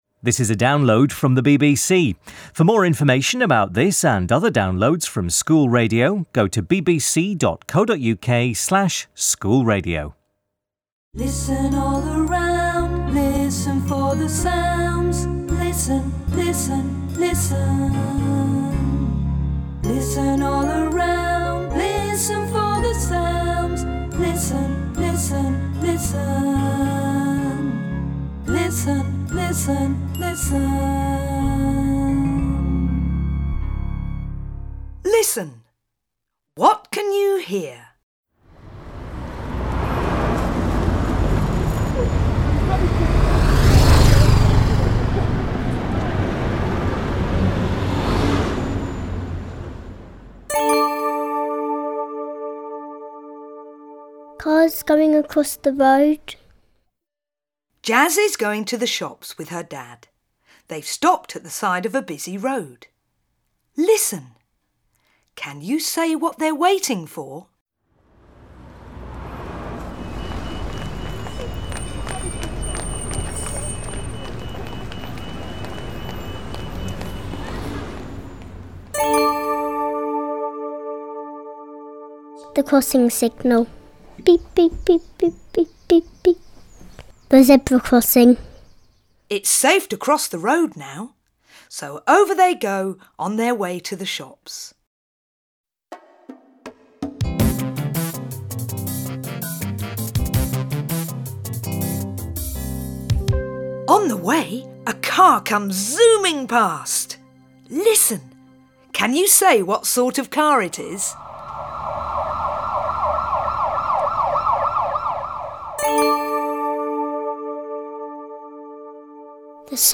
EYFS. Listening skills - 11: Sound story - BBC Teach
11_sound_story.mp3